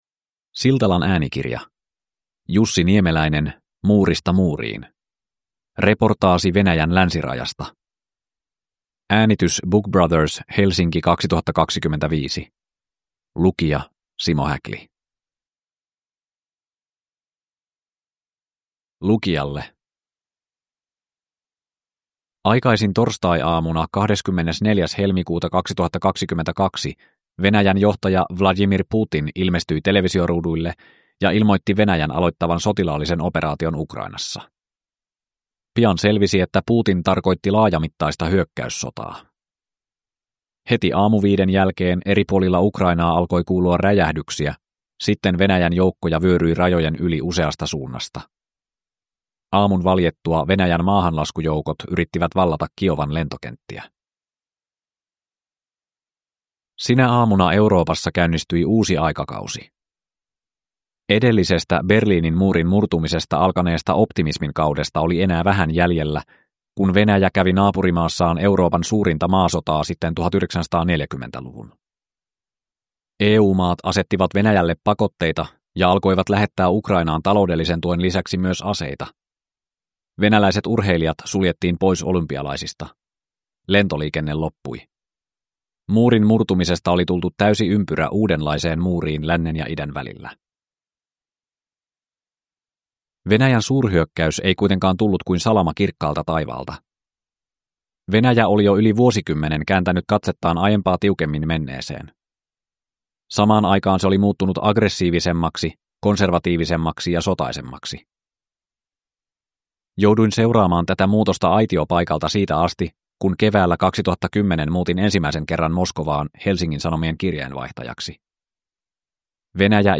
Muurista muuriin – Ljudbok